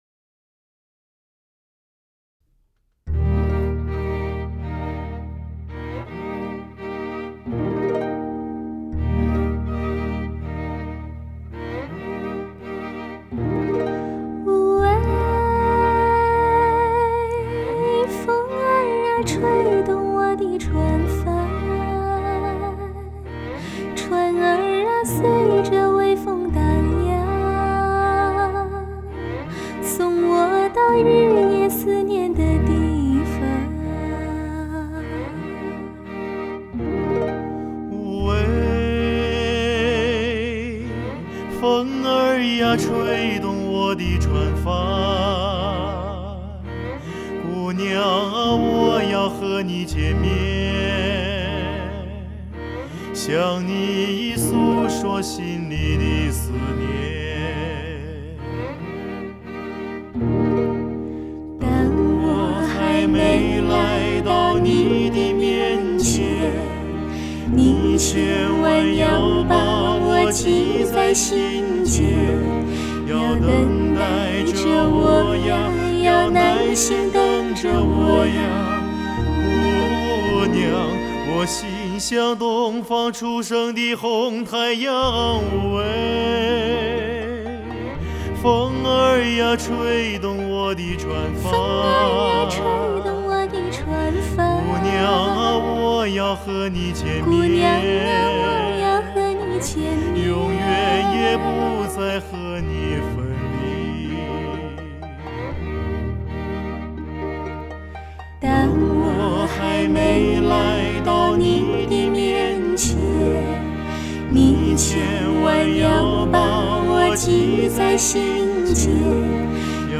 此碟由男女声以二重唱方式演出，发声点之精确、空间感之佳和人声之甜润尽显真空管录音之优点